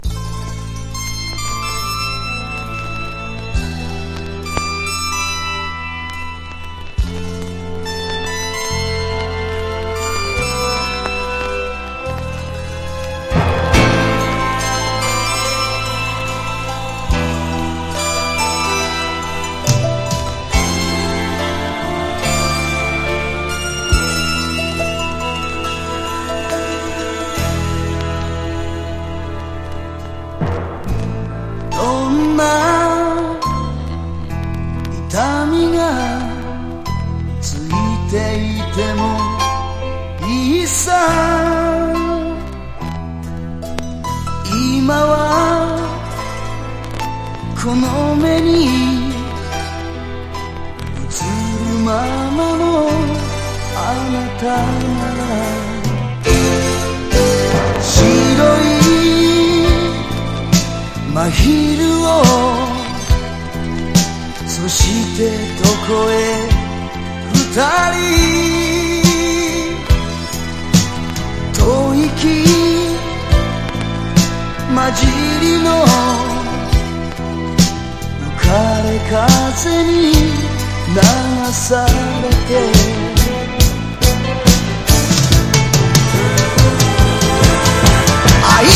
形式 : 7inch
コズミックシンセとストリングスがドラマチック！
ポピュラー# SOUNDTRACK / MONDO